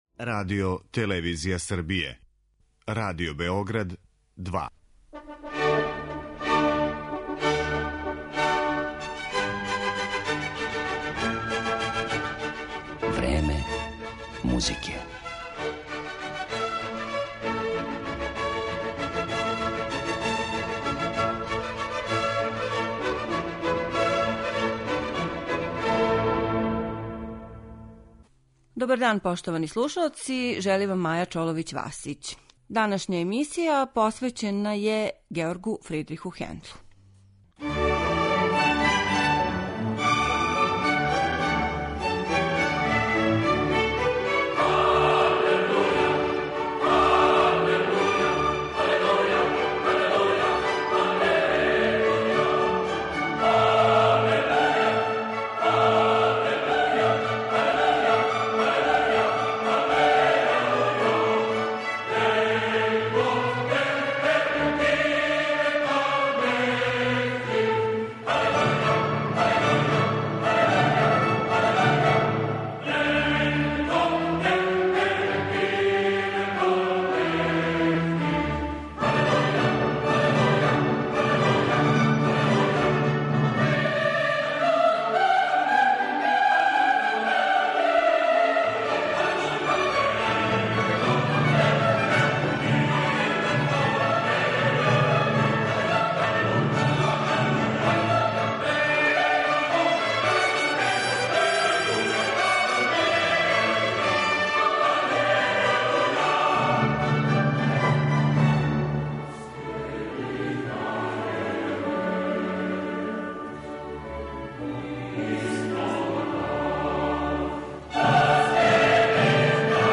Емитоваћемо снимке најистаканутијих солиста и ансамбала специјализованих за музику барока.